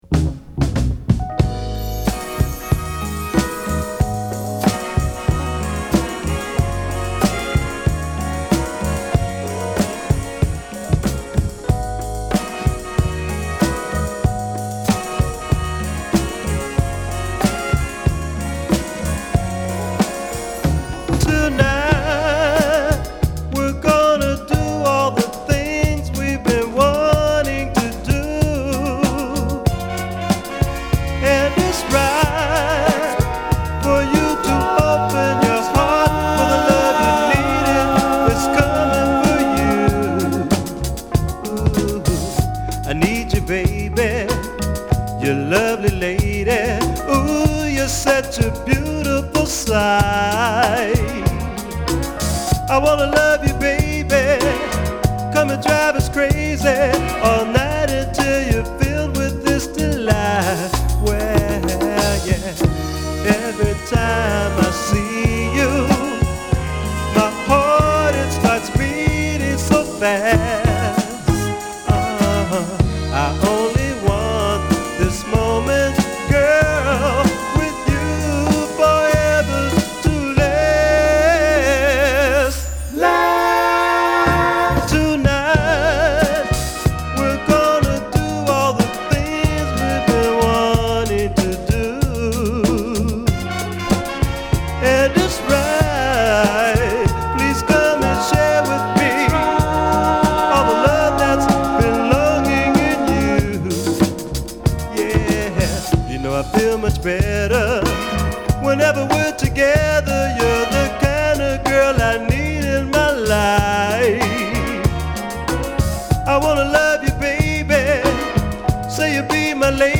本作ではファンクでは無く、ホッコリイナたくモダンでメロウなソウルを披露！